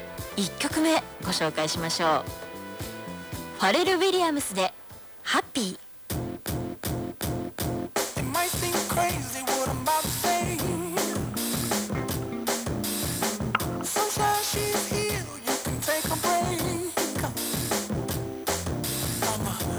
イヤホン端子に ＩＣ録音機を繋ぎ録音